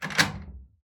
doorlock.wav